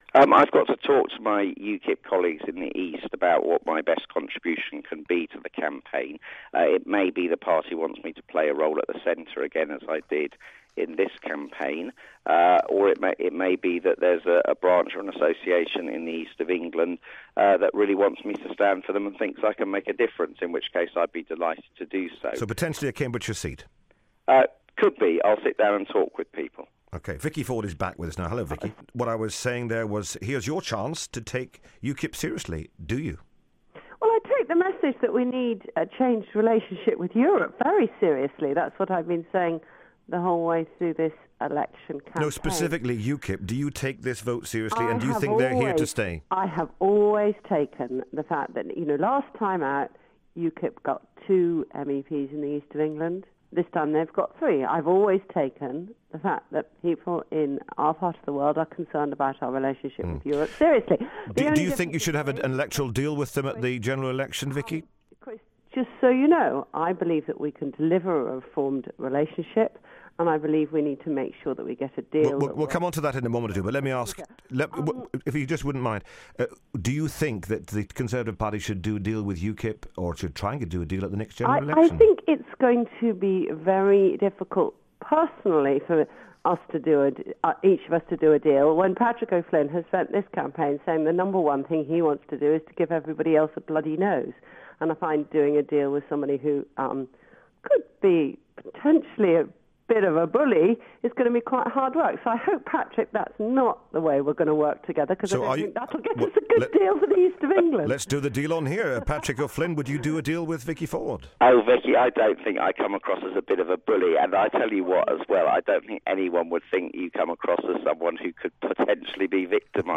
Patrick O'Flynn for UKIP and Conservative VIcky Ward - both MEPs for the Eastern Region discuss the chances of their parties co-operating.